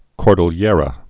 (kôrdl-yârə, kôr-dĭlər-ə)